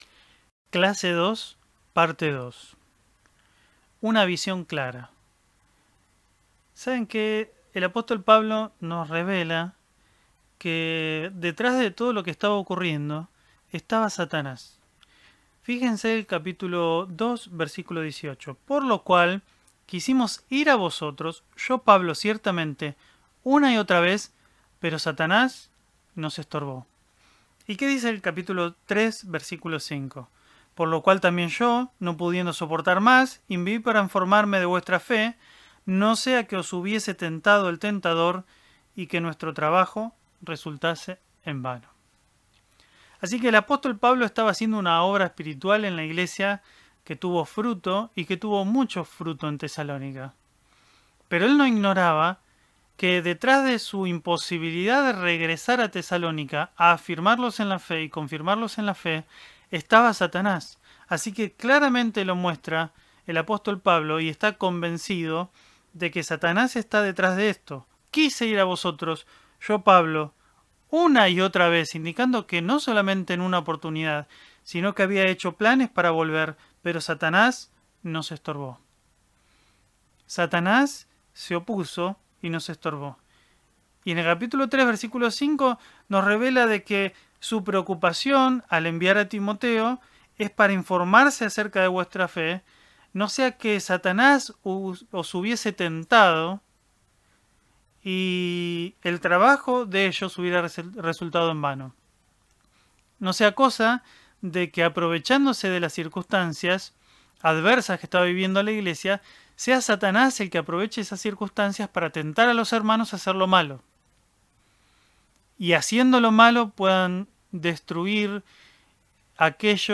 En esta segunda clase del estudio de la carta a los Tesalonicenses realizado por la Escuela Bíblica de Ibew.